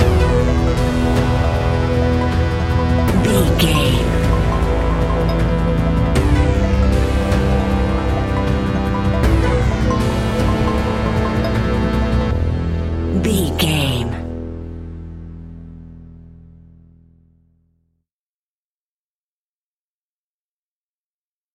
In-crescendo
Aeolian/Minor
ominous
dark
eerie
synthesizer
electronic music
electronic instrumentals
Horror Synths